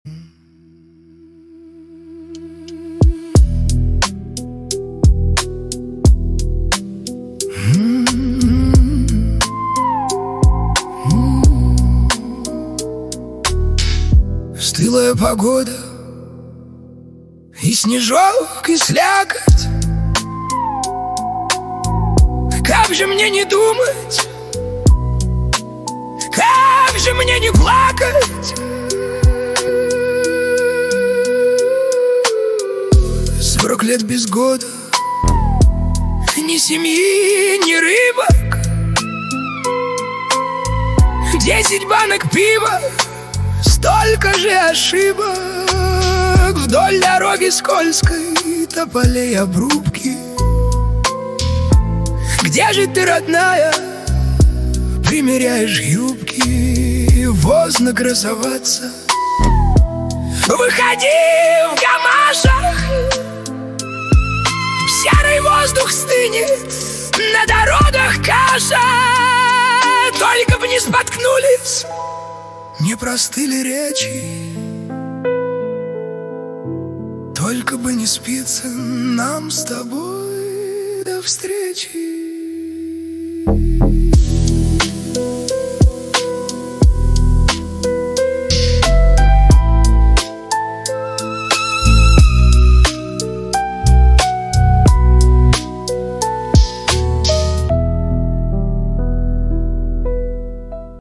Качество: 320 kbps, stereo
Русские поп песни, Русские треки
Песня ии нейросети